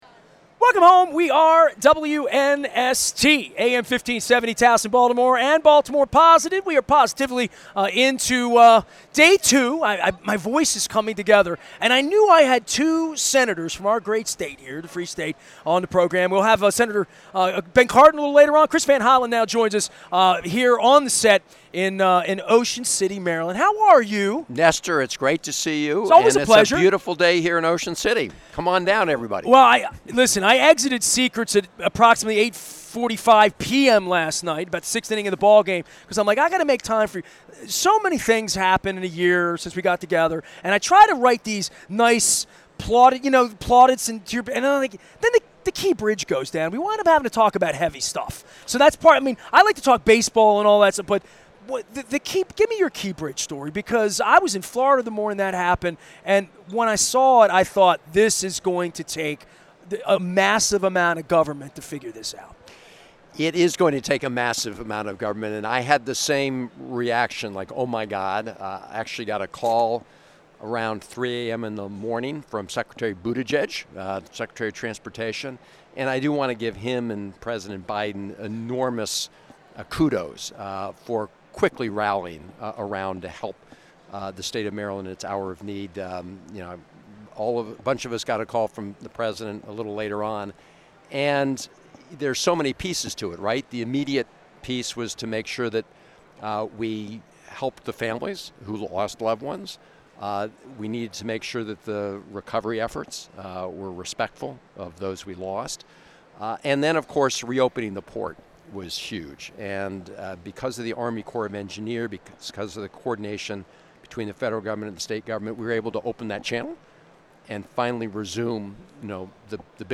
at MACo in Ocean City